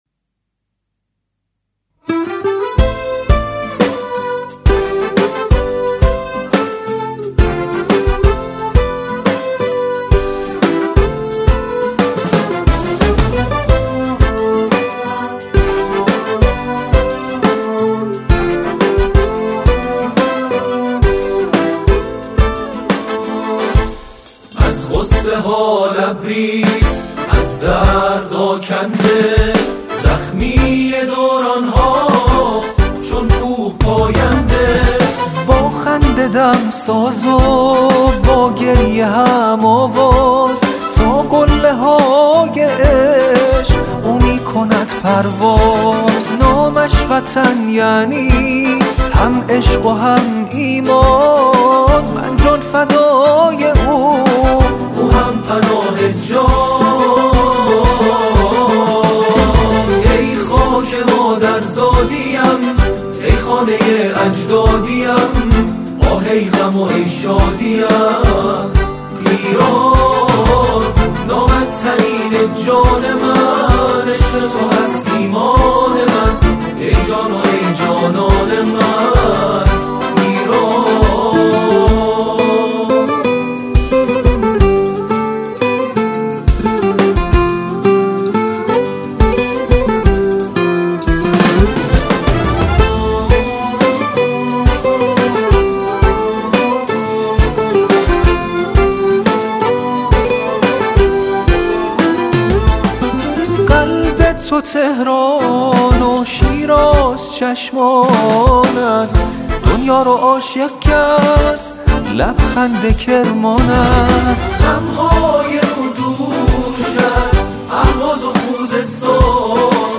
شعری را با موضوع ایران همخوانی می‌کنند.